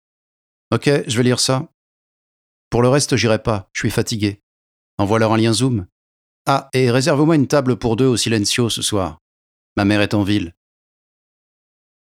Bandes-son
Doublage marionette
- Baryton-basse